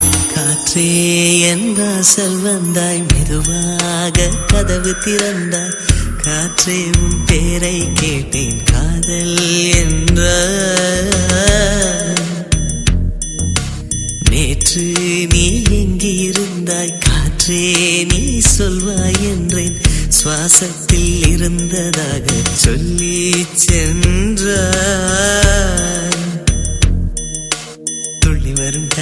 love song ringtone